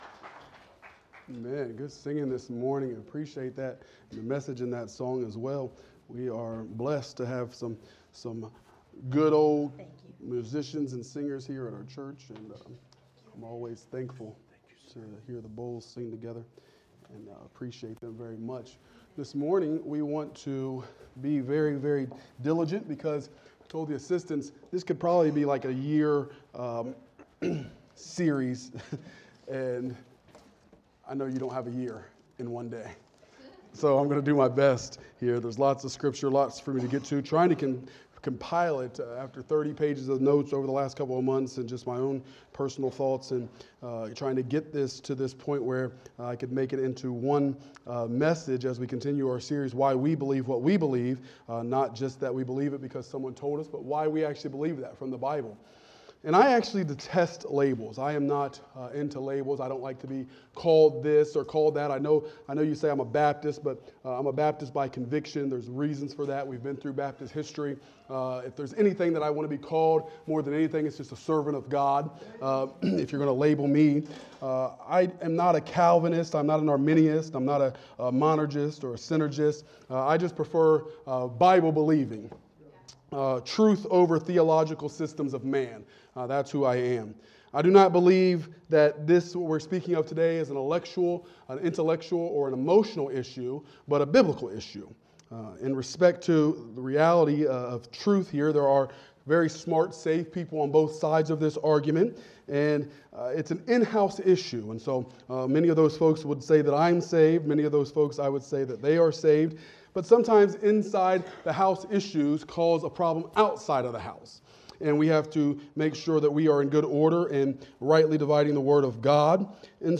Morning Worship Service